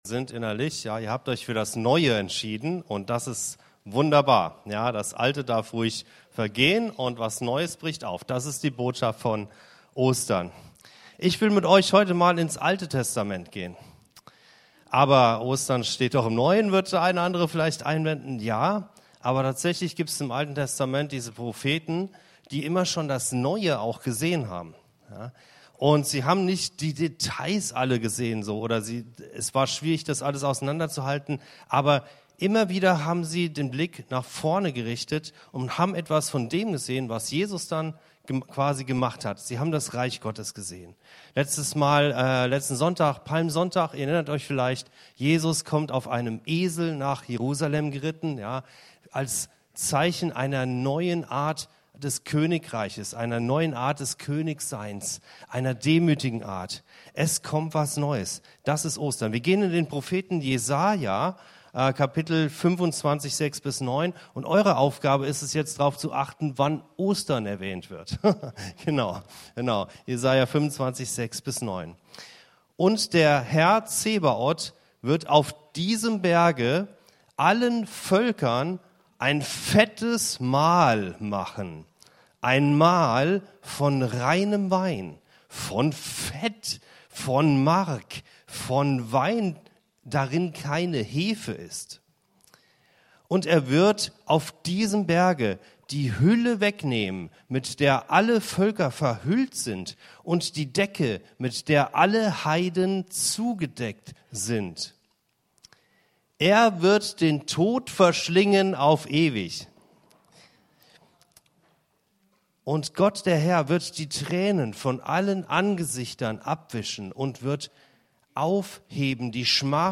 In dieser Osterpredigt wird die Vision aus Jesaja 25 entfaltet: Gott bereitet ein Fest für alle Völker, nimmt die Decke von den Augen und besiegt den Tod.